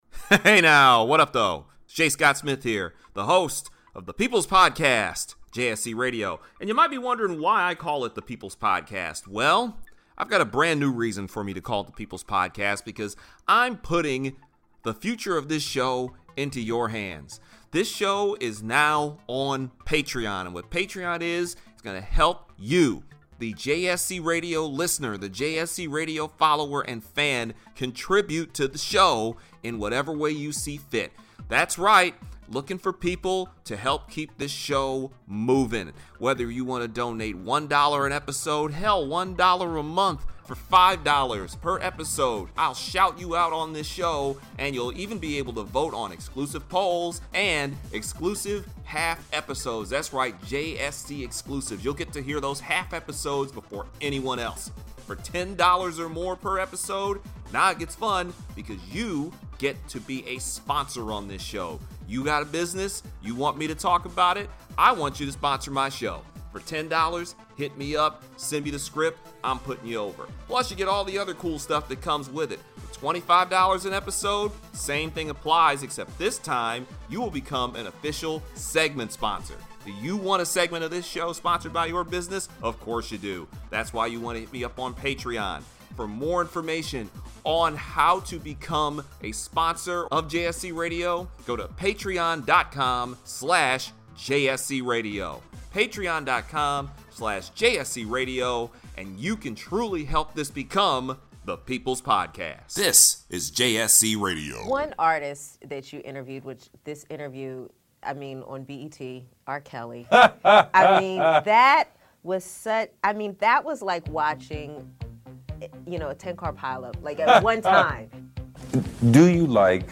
We're coming after R. Kelly and Michael Vick in a special way this week as the show goes back into the Feel The Hunger Radio archives and pulls out the epic 2008 rant when Kelly was strangely acquitted and we talk about why Black Women are the forgotten victims in all of this.